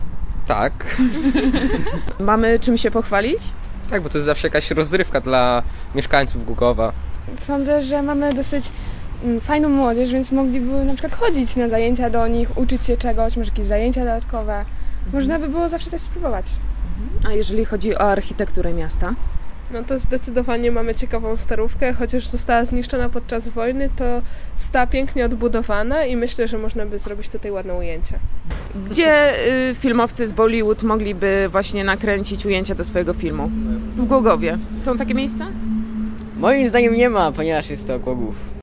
Posłuchaj opinii mieszkańców
0927_sonda_bollywood.mp3